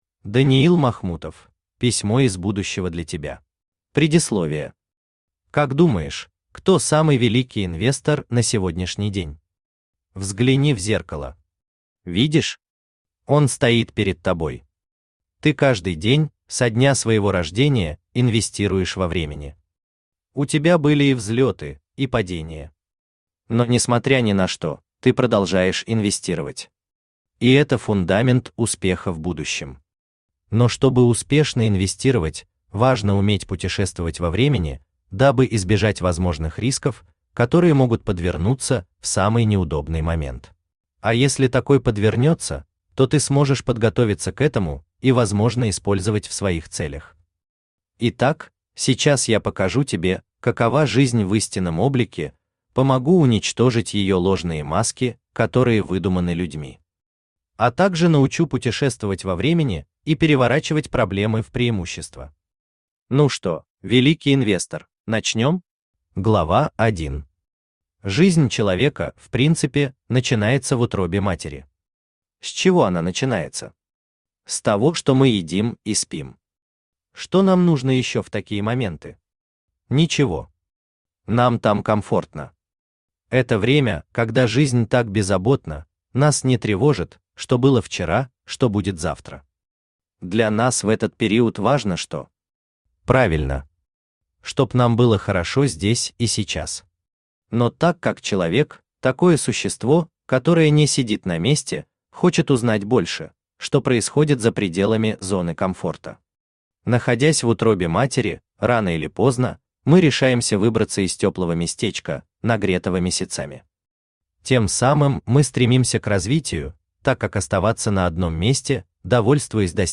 Аудиокнига Письмо из будущего для тебя | Библиотека аудиокниг
Читает аудиокнигу Авточтец ЛитРес.